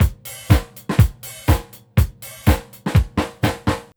INC BEAT1.wav